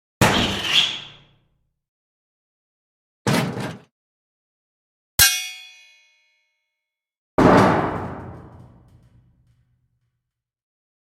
Metal Strikes Var 2
SFX
yt_UwQvJXVWPl4_metal_strikes_var_2.mp3